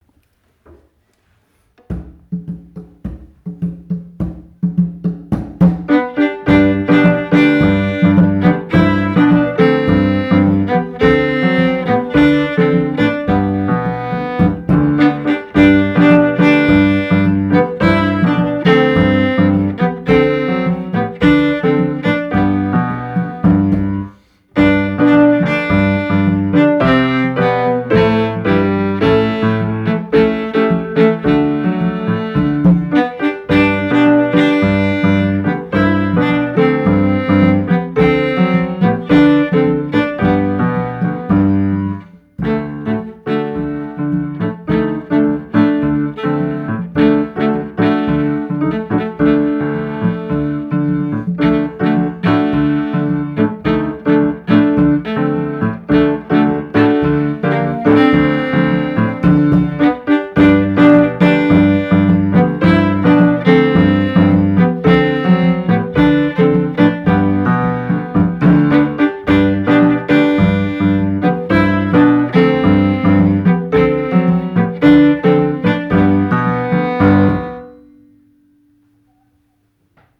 Übungsmaterial zur Orchesterfahrt 2018
Bratsche: